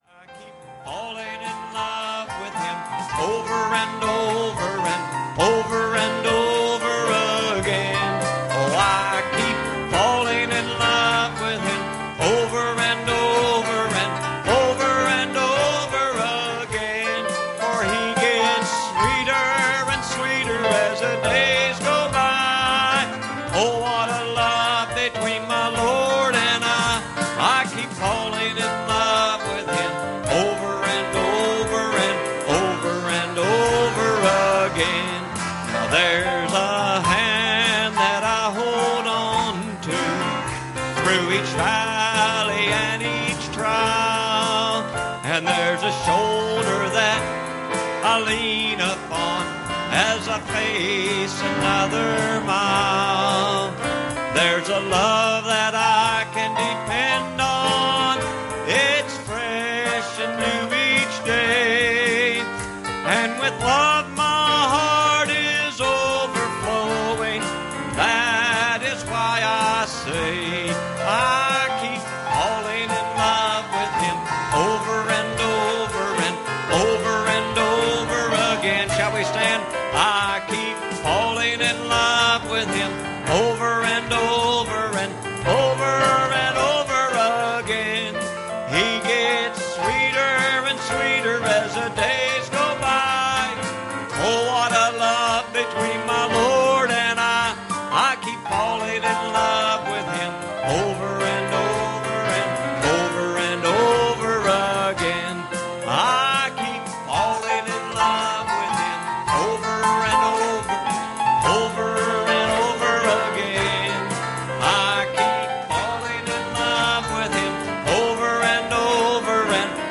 Revelation 1:9 Service Type: Sunday Evening "All right